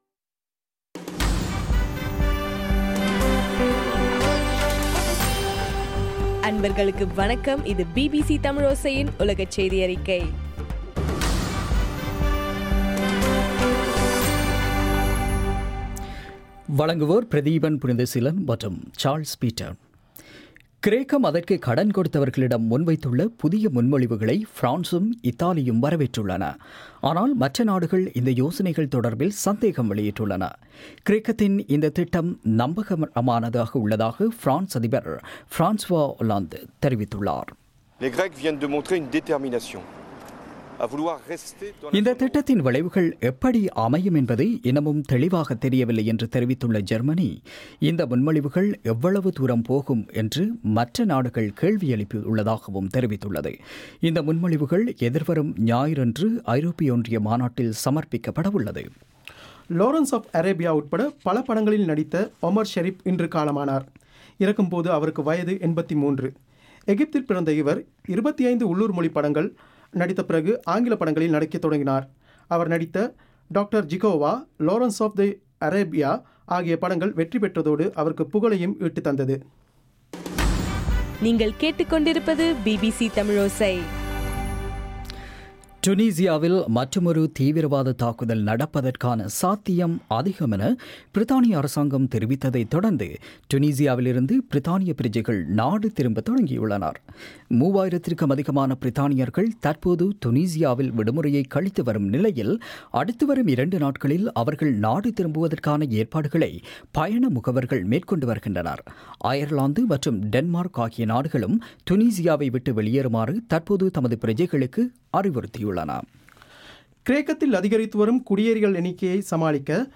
ஜூலை 10, 2015 பிபிசி தமிழோசையின் உலகச் செய்திகள்